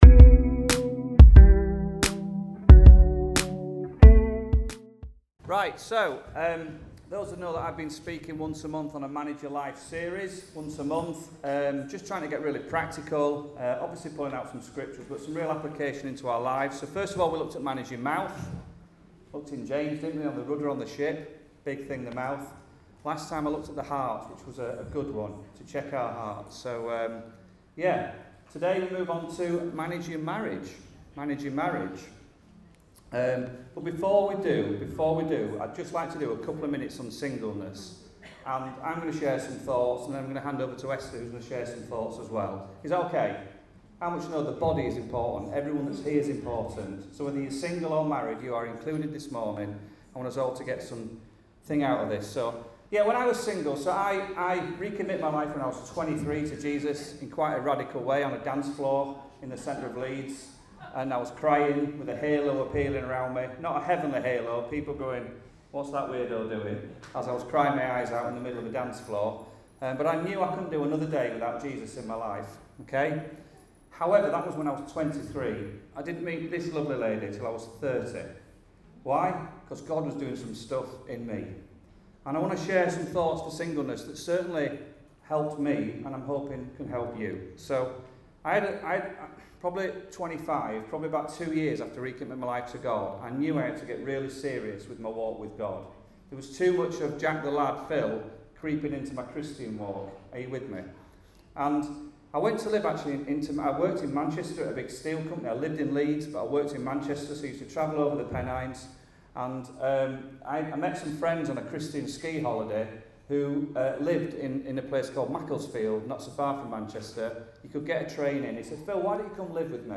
Sunday Messages Manage your Life